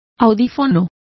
Complete with pronunciation of the translation of earphones.